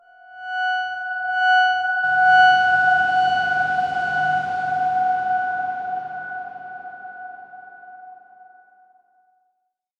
X_Darkswarm-F#5-mf.wav